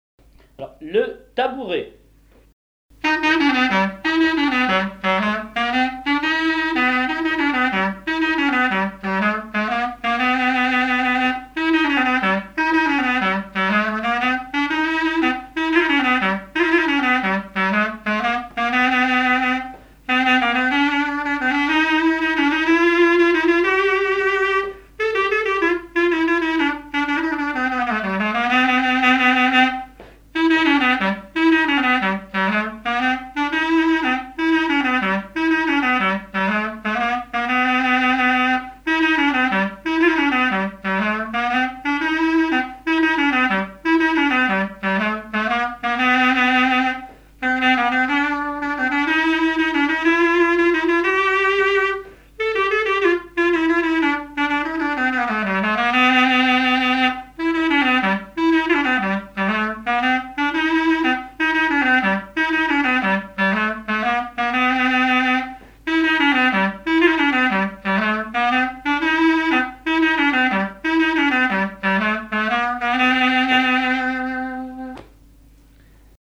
Chants brefs - A danser
Pièce musicale inédite